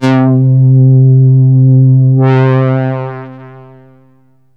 STRINGS 0010.wav